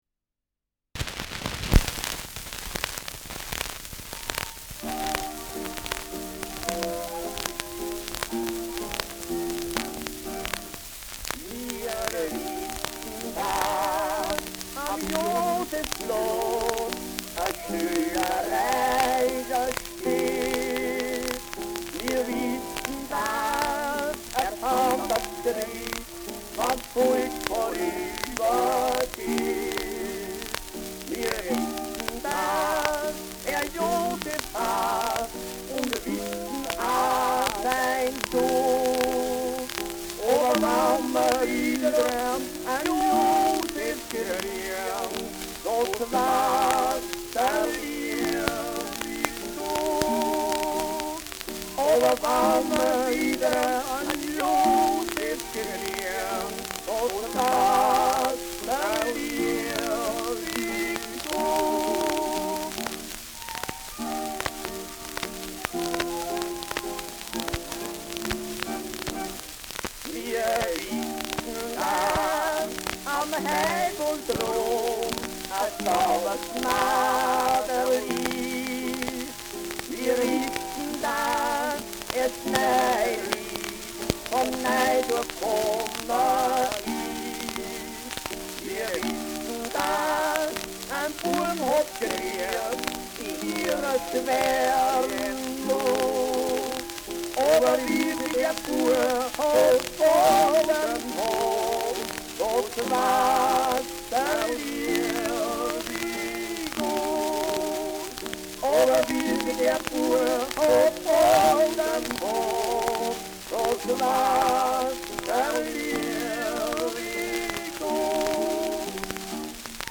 Schellackplatte
Tonrille: Kratzer Stark durchgehend
Starkes Grundrauschen : Starkes Knistern durchgehend